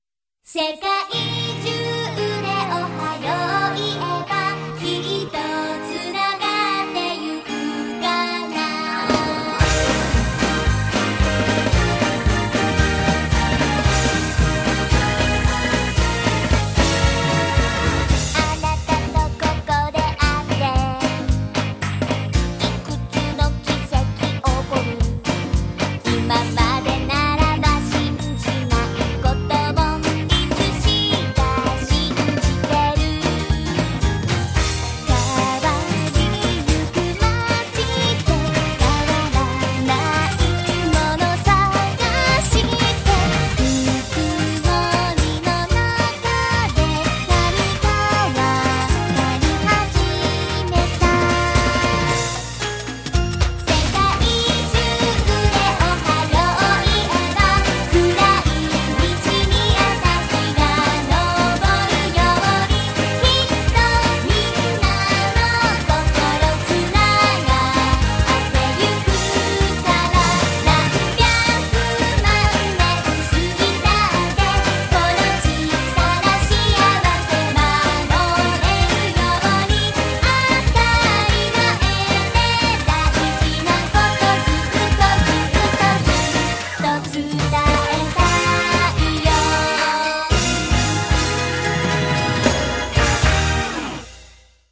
normalized to -0dB